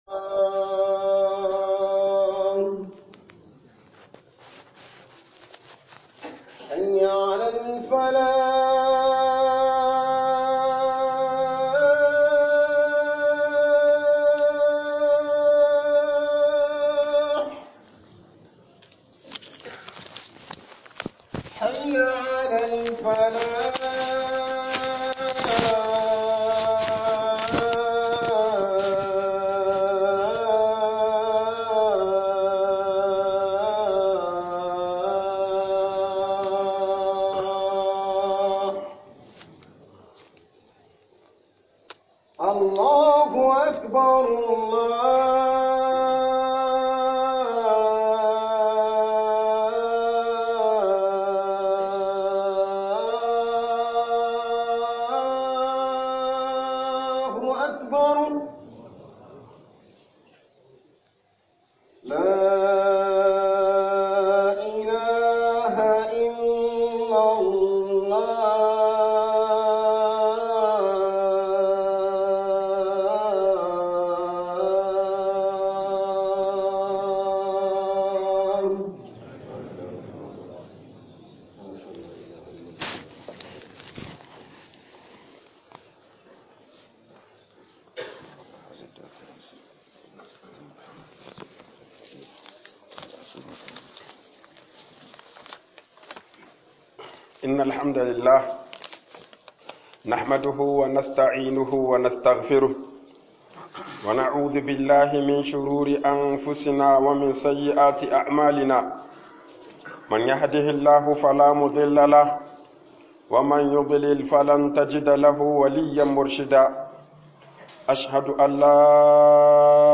HUDUBA SIDDIQ (2) (2) - HUƊUBOBIN JUMA'A